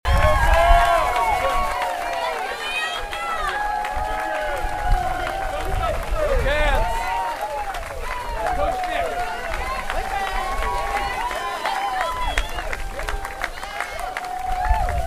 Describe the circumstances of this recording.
Fans gathered outside the Ice Family Basketball Center Thursday morning as the Wildcats departed for Spokane, Washington to play in the program’s first Sweet Sixteen since 2002. KSU-sendoff-nat-sound.mp3